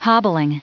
Prononciation du mot hobbling en anglais (fichier audio)
Prononciation du mot : hobbling